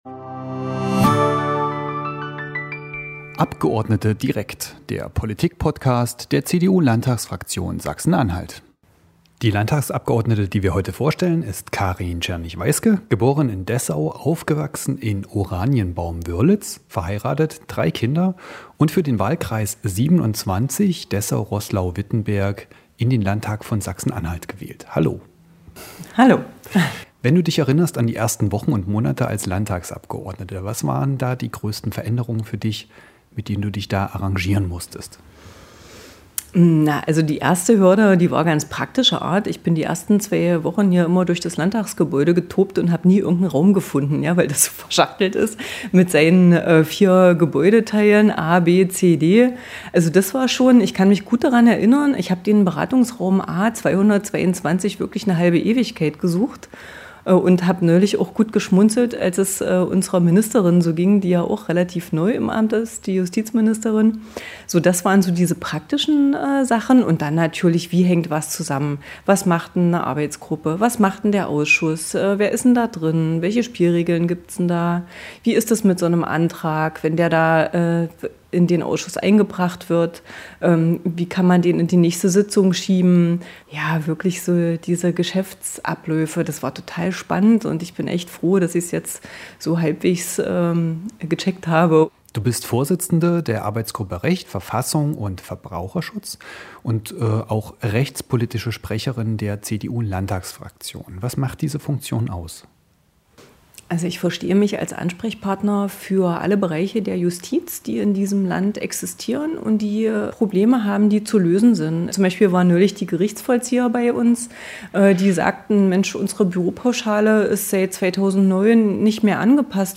Im Podcast spricht Frau Tschernich-Weiske über ihre